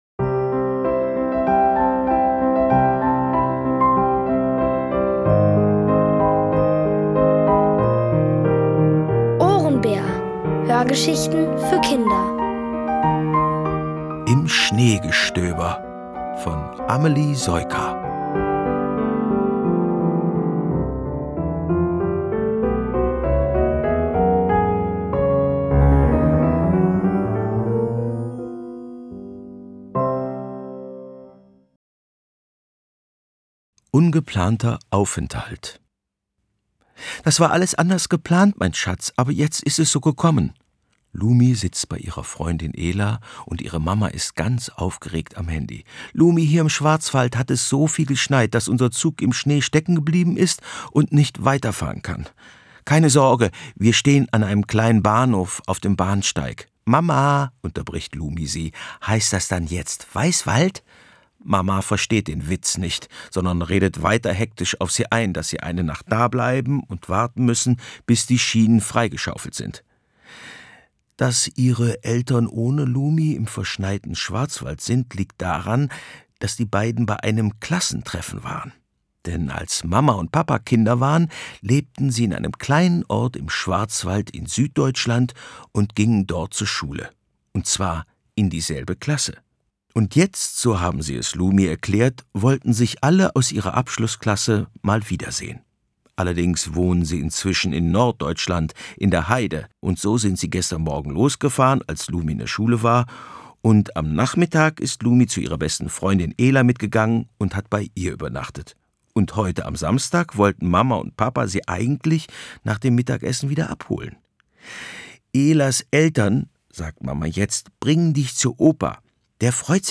Von Autoren extra für die Reihe geschrieben und von bekannten Schauspielern gelesen.
Es liest: Bernhard Schütz.